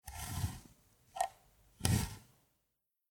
Mechanische Rechenmaschine "Facit"